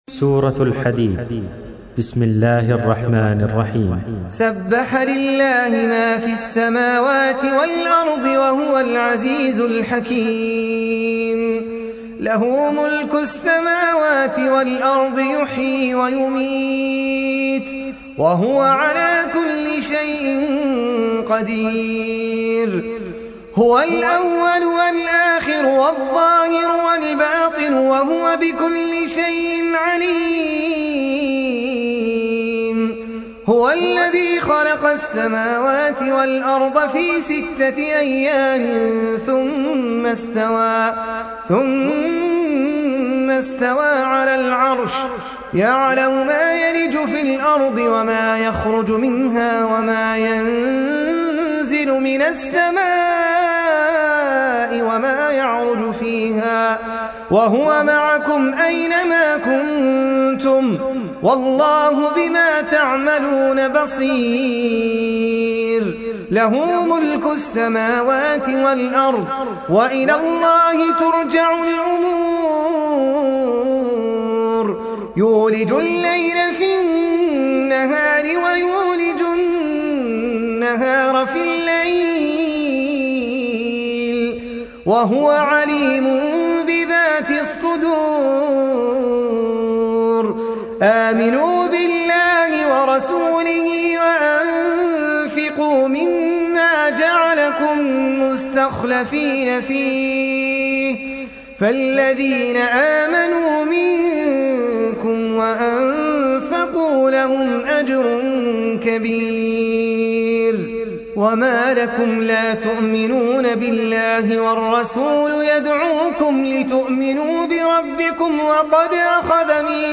سورة الحديد | القارئ أحمد العجمي